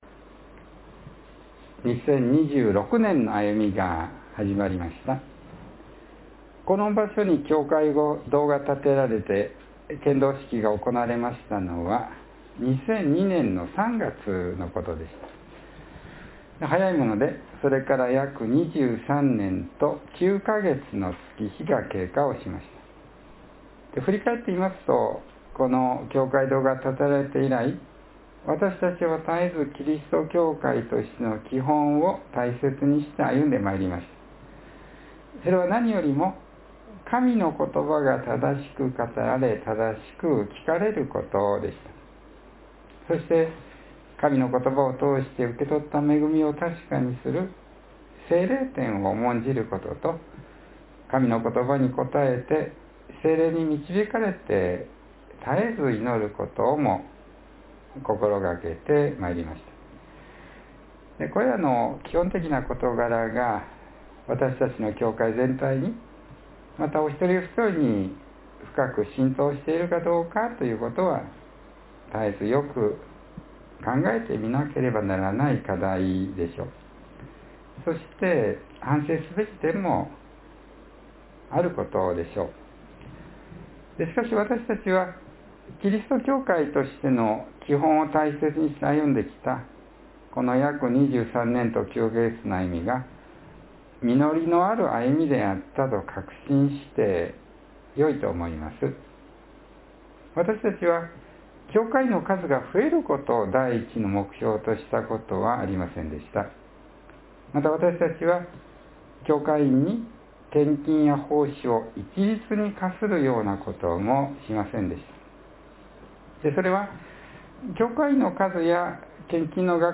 （1月4日の説教より）